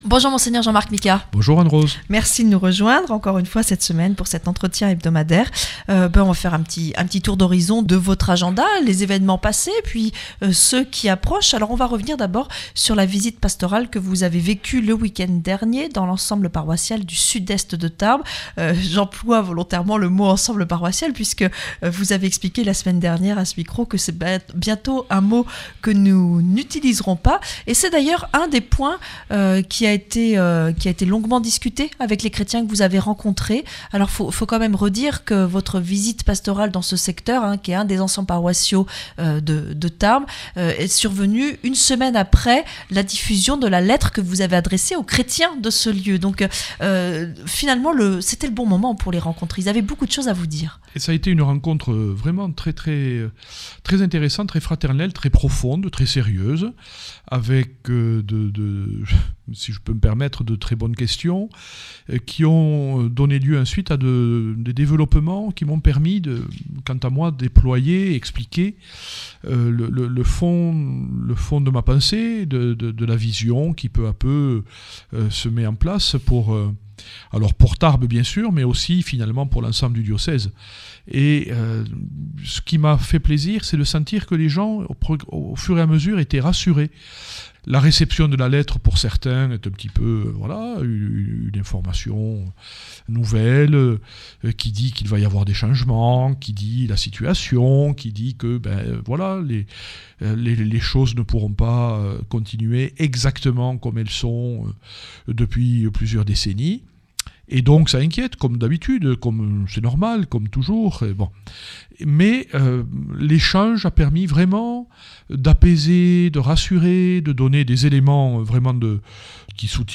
Cette semaine dans l’entretient de Mgr Jean-Marc Micas, il va être beaucoup question de la réorganisation de l’Eglise dans notre diocèse.